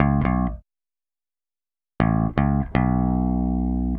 Power Pop Punk Bass Intro 02.wav